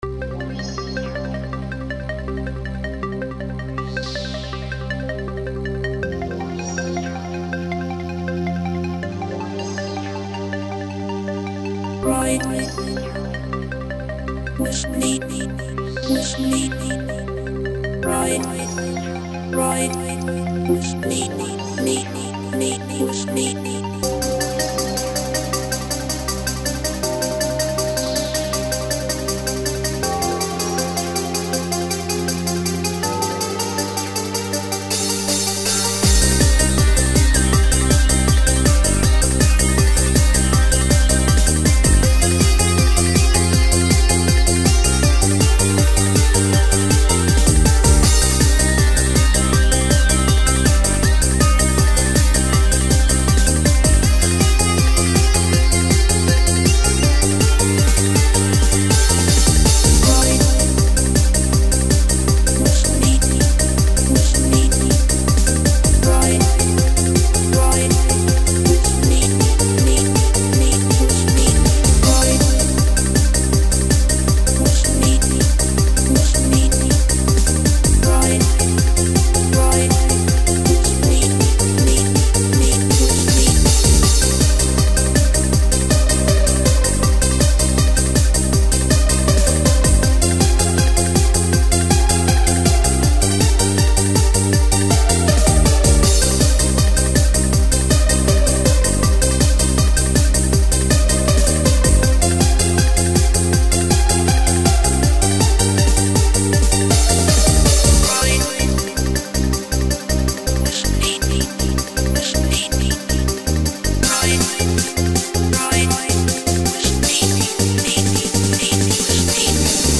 dance/electronic
Techno
Trance
Eighties/synthpop